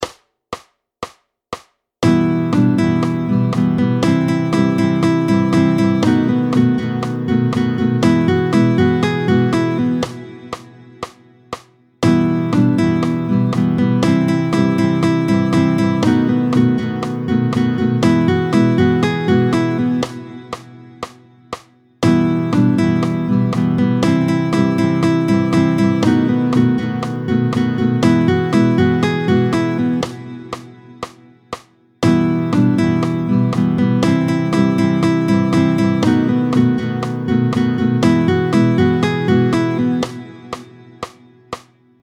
15-02 Ensemble.
Vite, tempo 120